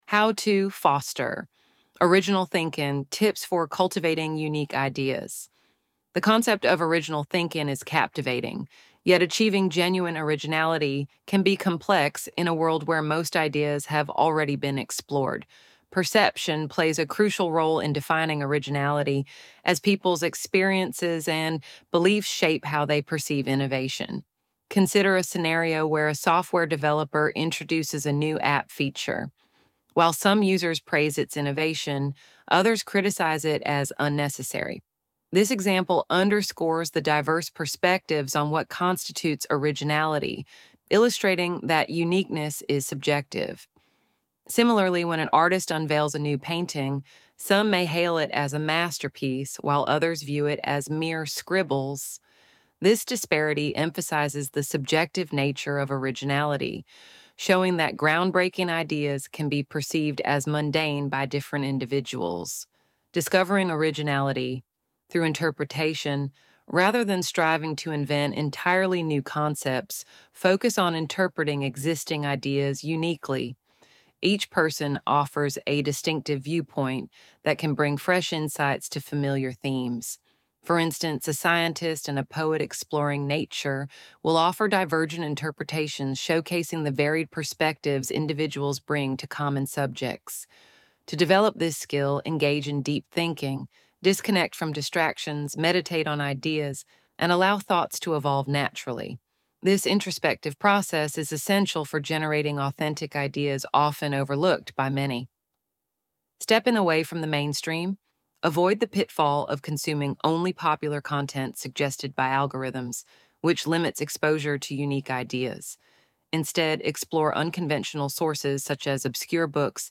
ElevenLabs_2025-Original-Thinking.mp3